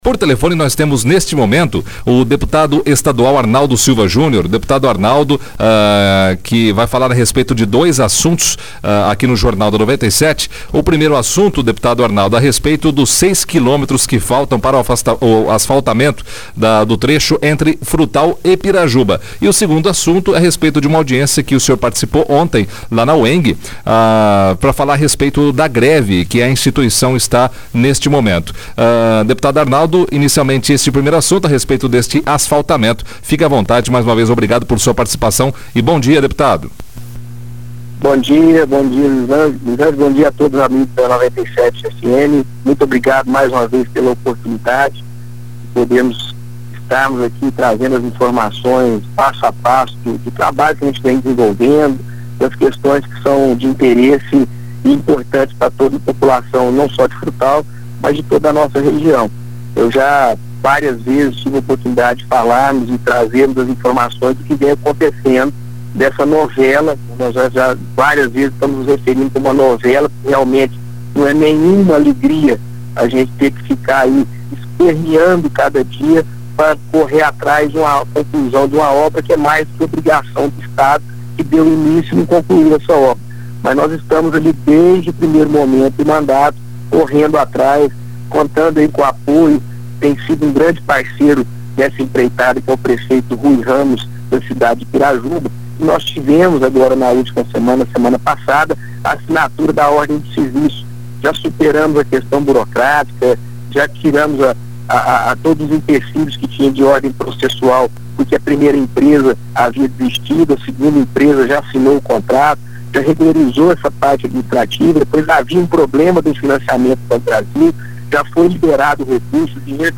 O Deputado Estadual Arnaldo Silva Júnior concedeu entrevista por telefone ao Jornal da 97, Segunda Edição, nesta terça-feira (24/05), onde abordou dois assuntos, sendo eles, a conclusão da pavimentação dos 6 Km que faltam entre Frutal e Pirajuba, e também sobre uma audiência em que o Deputado participou ontem na UEMG.